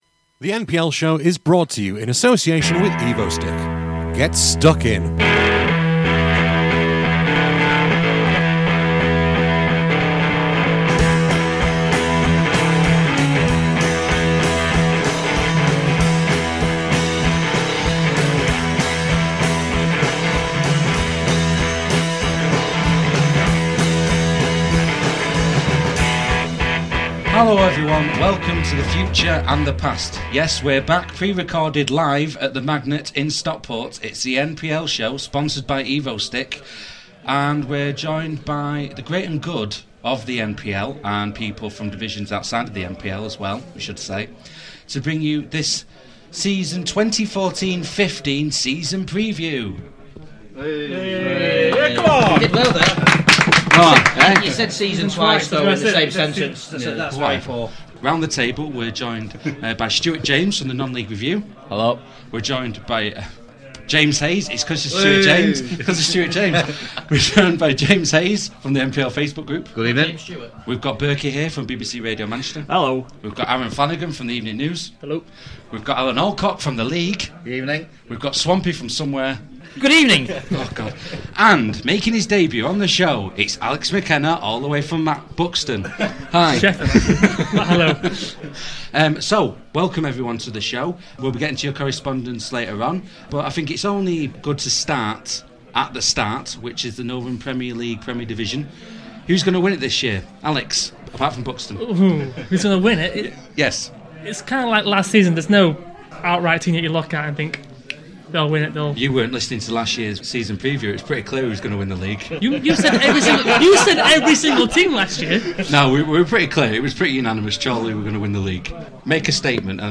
With Thanks: This programme was recorded at the Magnet in Stockport on Wednesday 13th August 2014.